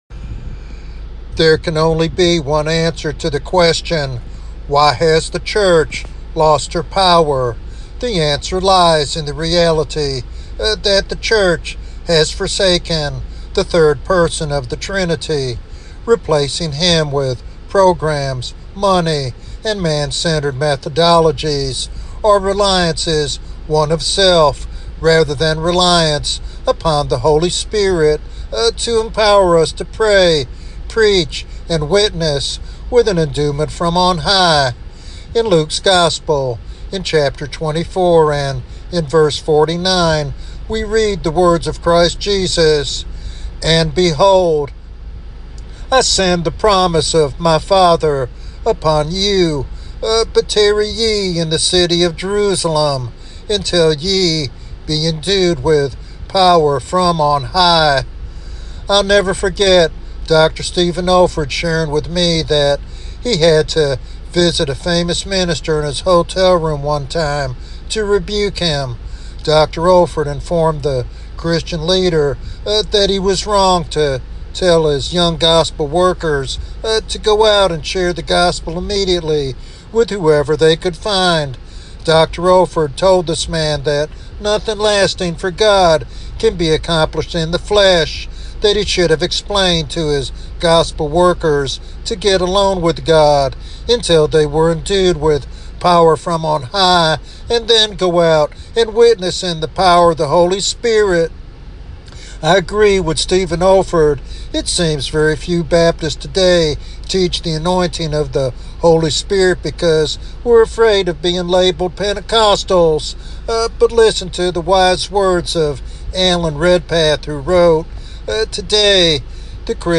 This sermon calls the church to awaken from spiritual lethargy and reclaim its God-given authority and effectiveness.
Sermon Outline